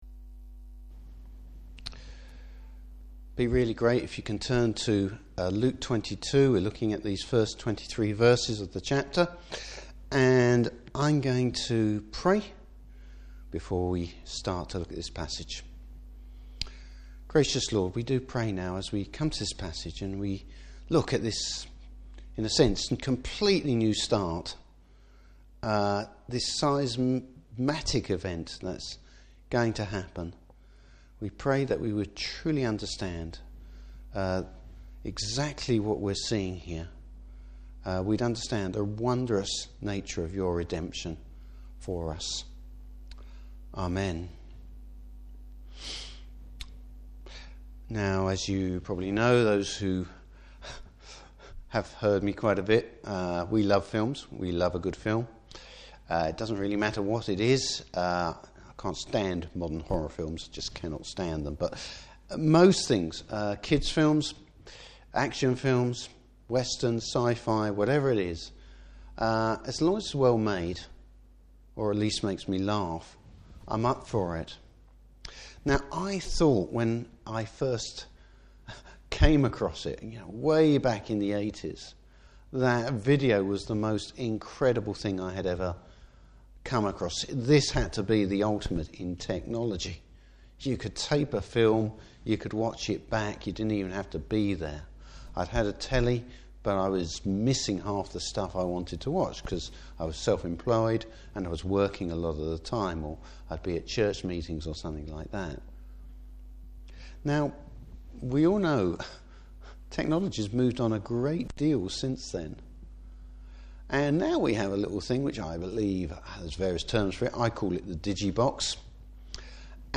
Service Type: Morning Service The new Covenant and remembrance of Christ’s work.